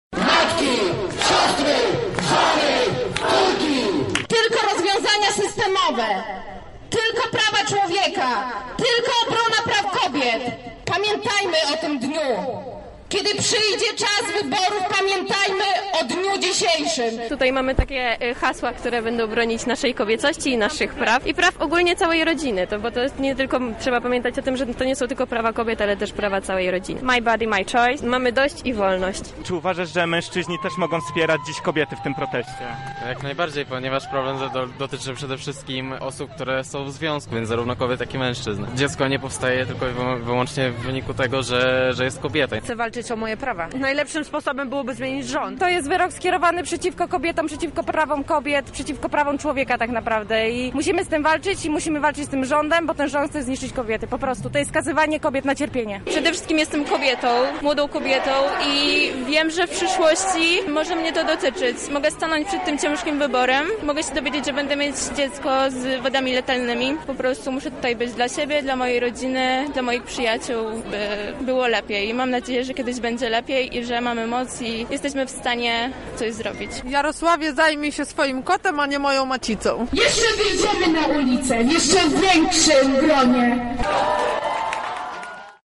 „Kiedy państwo nas nie broni, mojej siostry będę chronić” – takie hasło wykrzykiwał wczoraj tłum zgromadzony przed biurem Prawa i Sprawiedliwości w Lublinie.